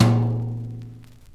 Dusty Tom 01.wav